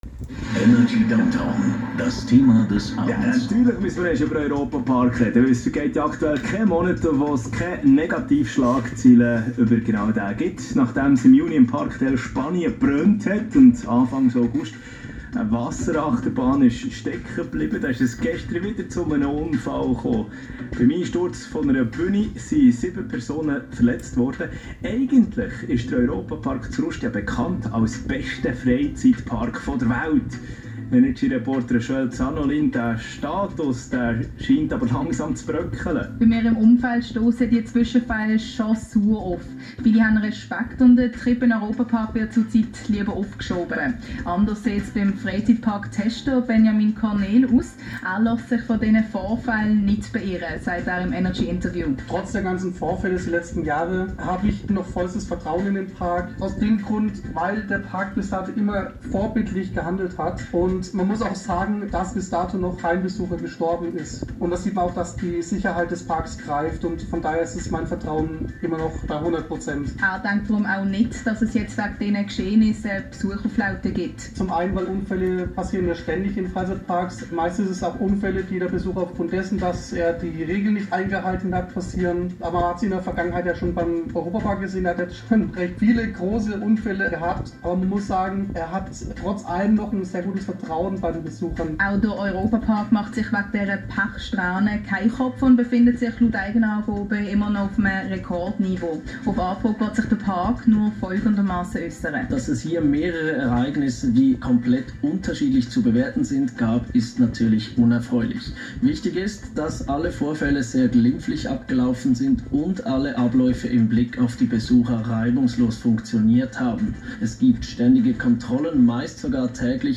Radioausschnitte:
Interview-Schweiz.mp3